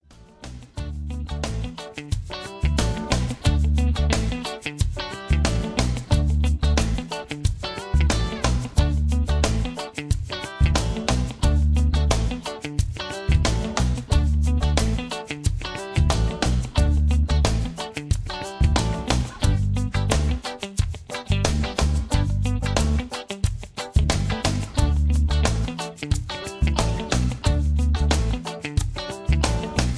backing tracks
rock